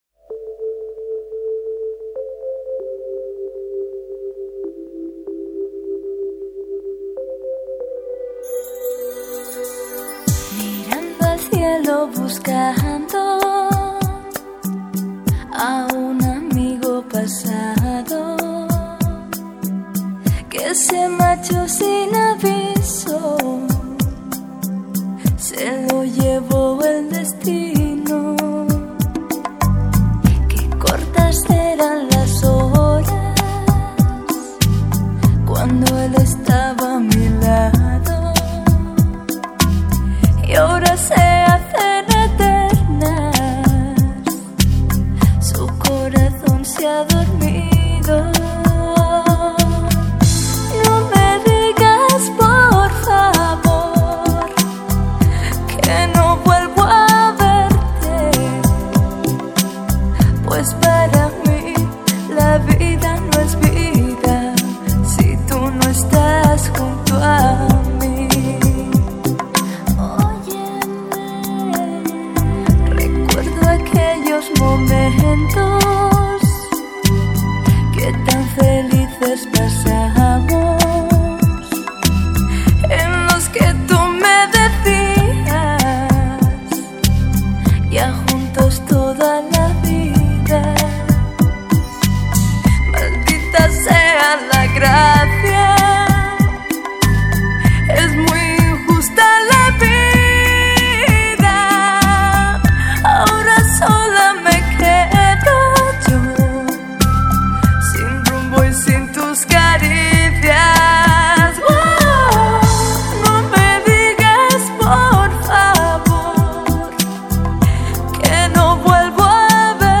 优美绝伦的西语伦巴